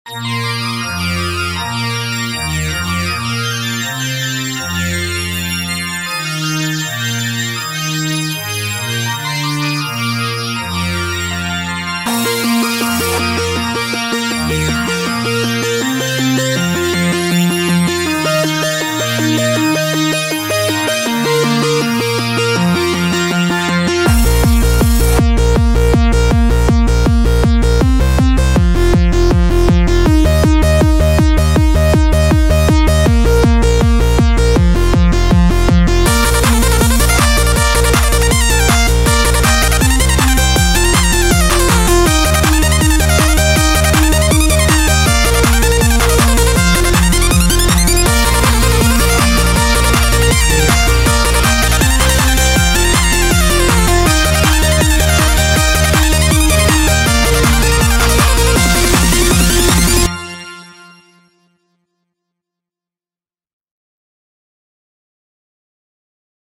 An instrumental recording.
This came straight off a CD of her music that I burned almost 20 years ago.
I've always just appreciated its upbeat, fun nature and the way the synths blend as they transition into each other.